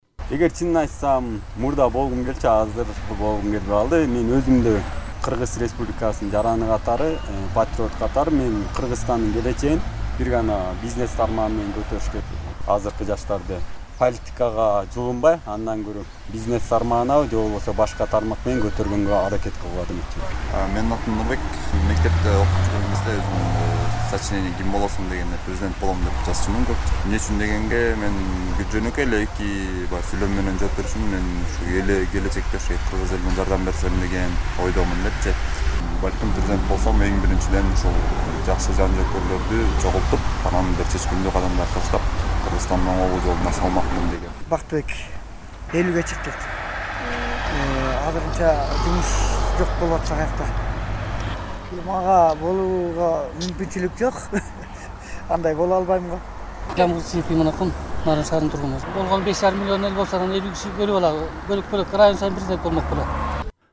Сурамжылоо - Нарын